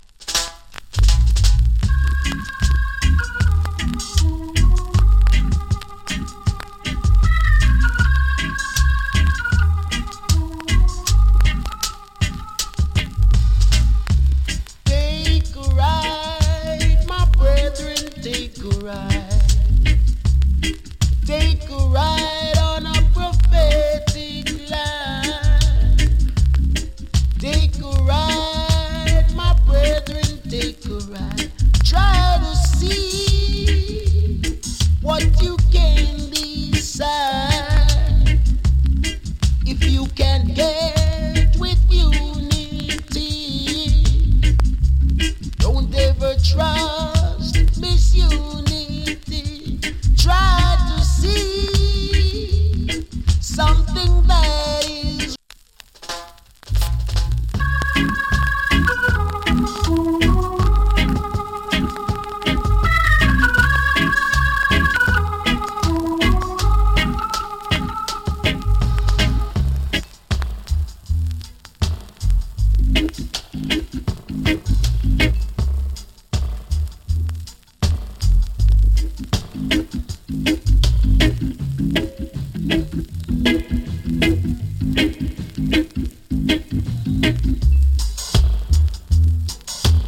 チリ、パチノイズ有り。